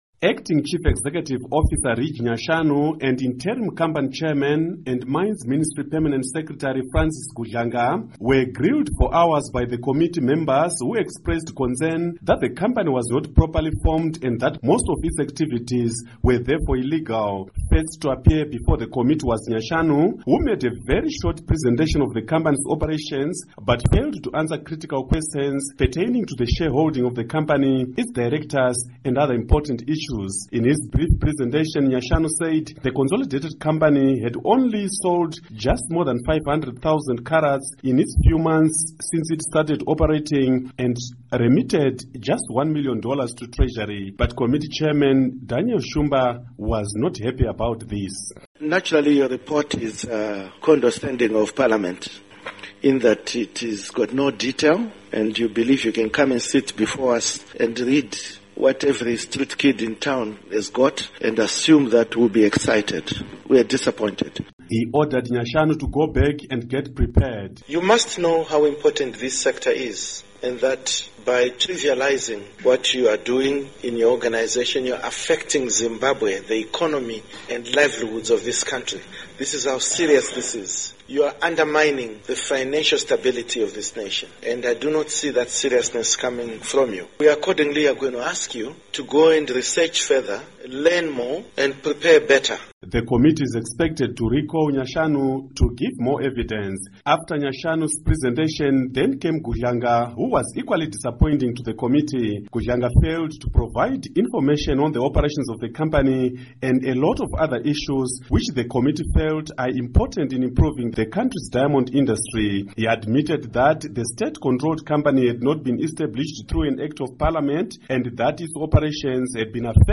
Report on Parly Diamonds Hearing